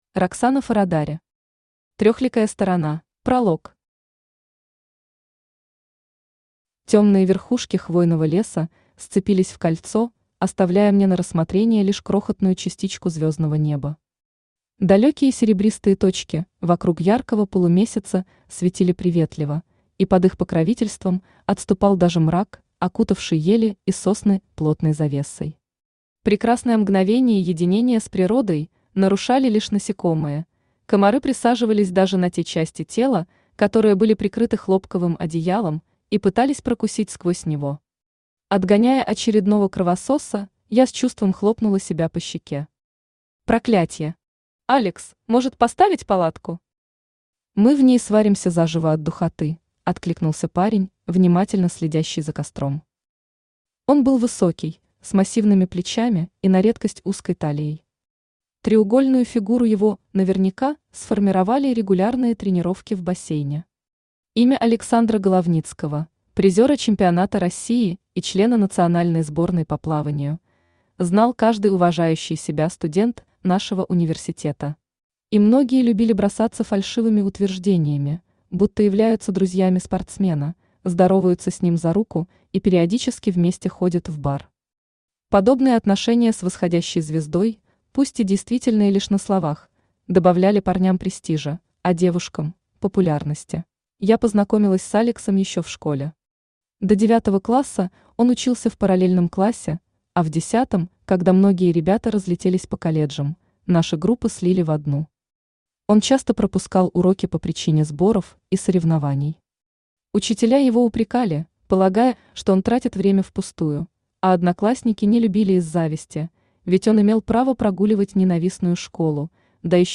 Аудиокнига Трехликая сторона | Библиотека аудиокниг
Aудиокнига Трехликая сторона Автор Роксана Форрадаре Читает аудиокнигу Авточтец ЛитРес.